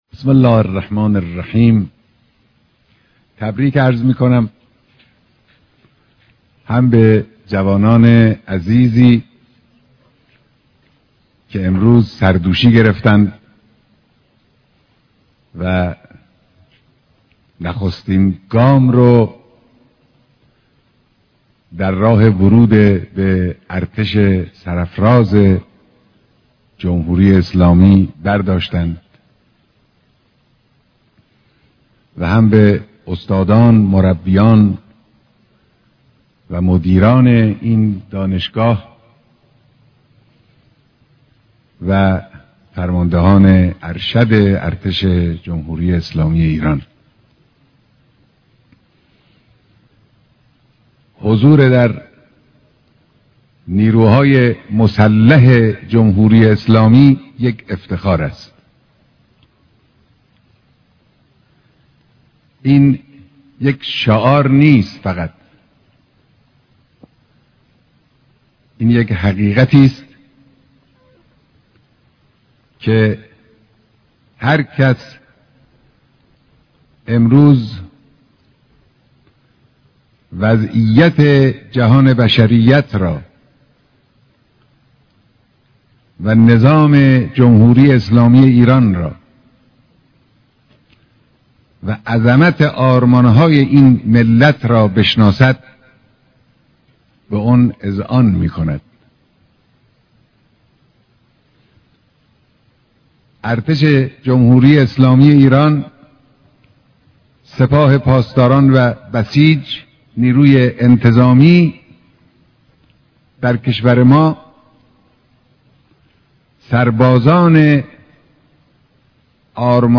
مراسم سومين دوره آموزش دانشجويان دانشگاههاي ارتش